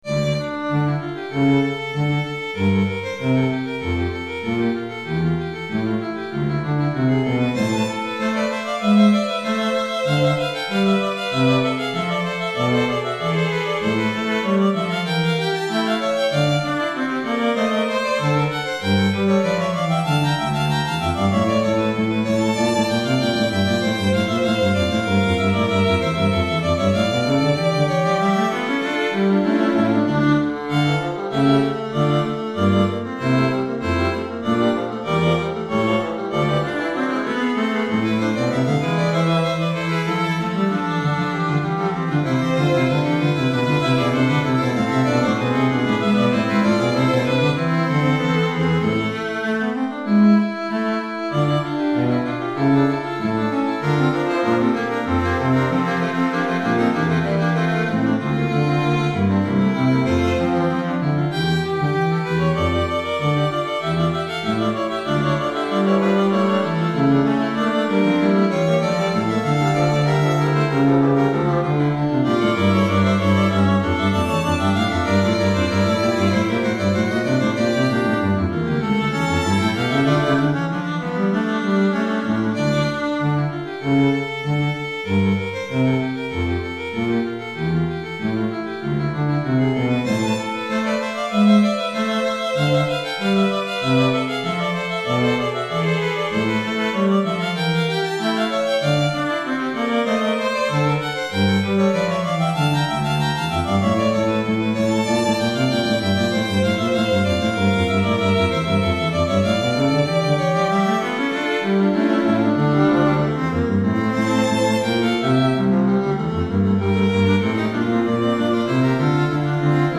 2 Violons, Alto et Violoncelle